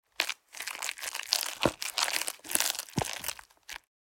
Download Free Cooking Sound Effects | Gfx Sounds
Stirring-cake-mixture-cake-batter.mp3